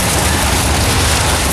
tyres_dirt_skid.wav